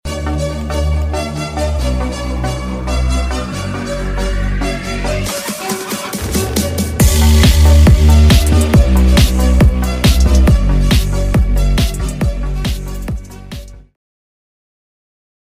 💨 MK F02 X20 Fog Jet sound effects free download
Just 4000W of explosive fog synced to the drop.